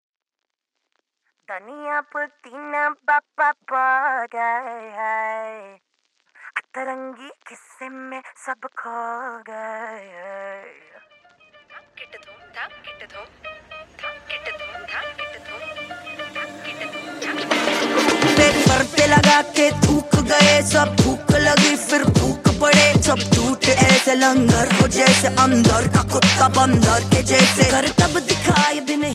Bollywood song ringtone for Android and iPhone mobile users.